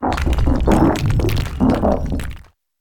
Cri de Gigansel dans Pokémon Écarlate et Violet.